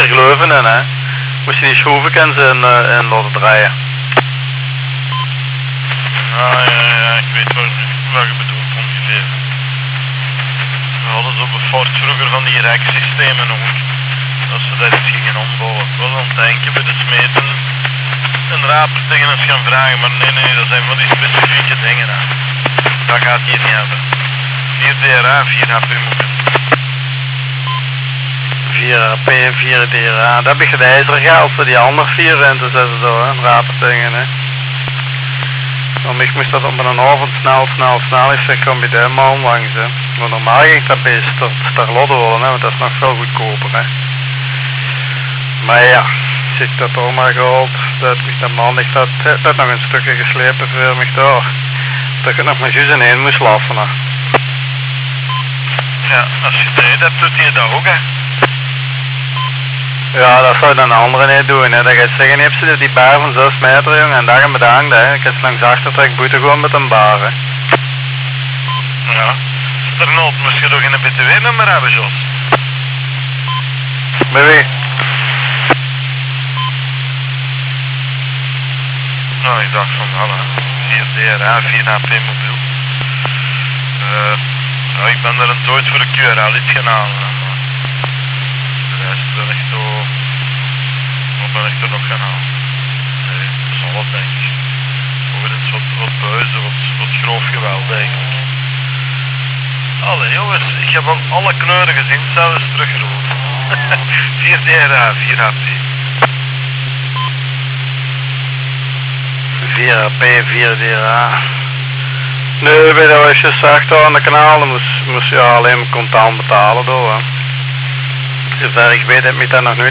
Monitoring frequency VHF/UHF/HF